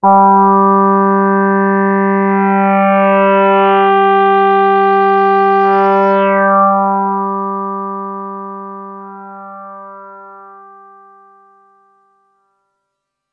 标签： FSharp4 MIDI音符-67 Korg的-Z1 合成器 单票据 多重采样
声道立体声